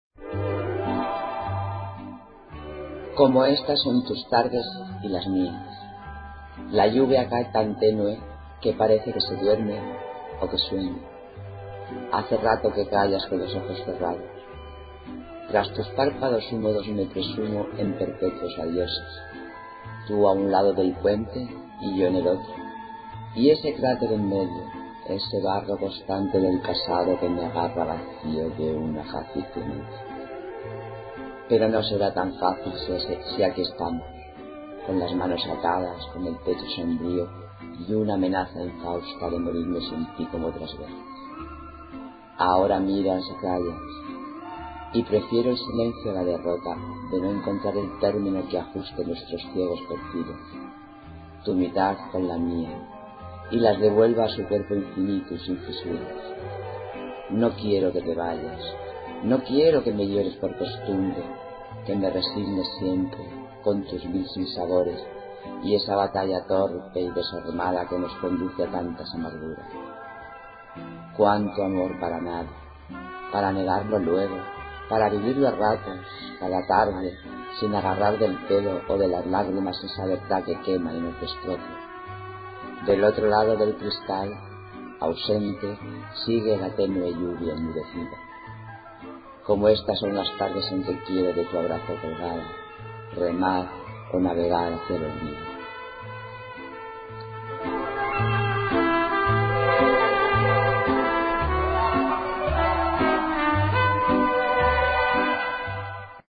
Inicio Multimedia Audiopoemas Tardes de lluvia.
V Certamen «Poemas sin Rostro» 2009-2010